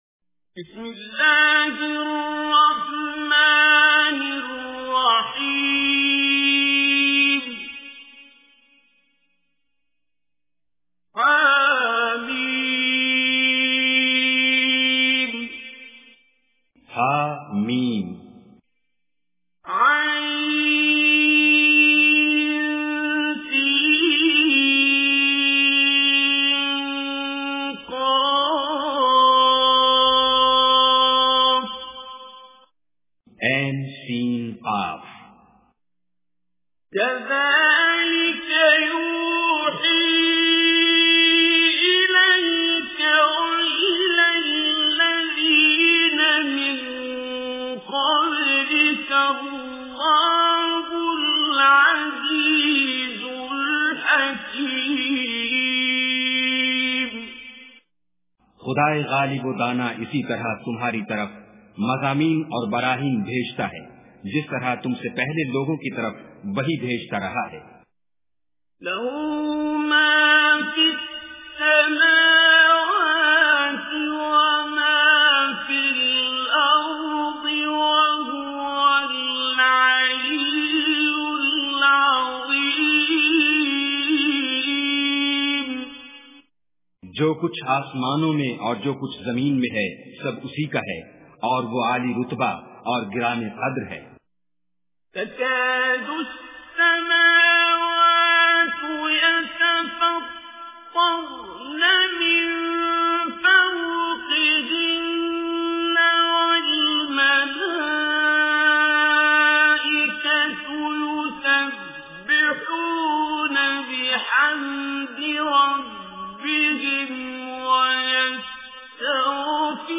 Surah Shura Recitation with Urdu Translation
Surah Shura is 42nd chapter of Holy Quran. Listen online and download mp3 tilawat / recitation of Surah Ash Shuraa in the voice of Qari Abdul Basit As Samad.